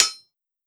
Blacksmith hitting hammer 4.wav